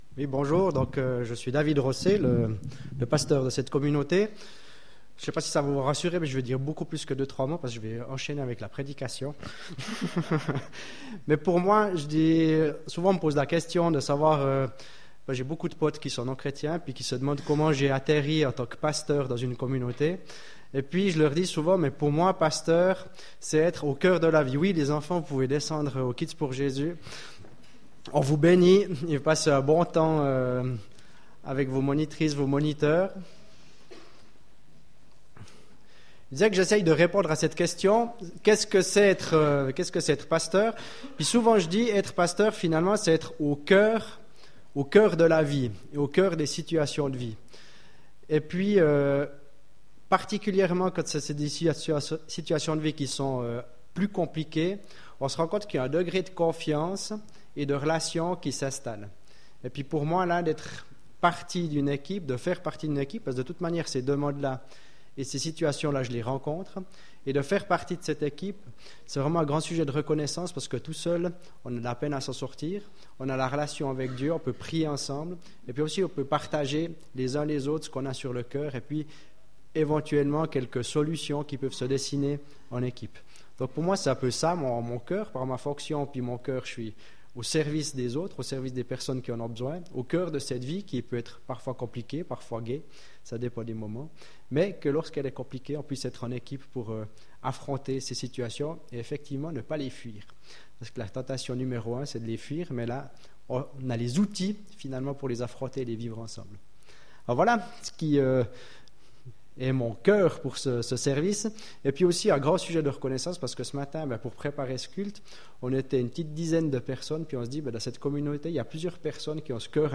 Culte du 5 mars 2017 « Une parole pour chacun »